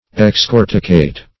Search Result for " excorticate" : The Collaborative International Dictionary of English v.0.48: Excorticate \Ex*cor"ti*cate\, v. t. [L. ex out, from + cortex, corticis, bark.] To strip of bark or skin; to decorticate.